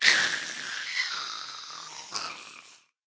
minecraft / sounds / mob / ghast / death.ogg
death.ogg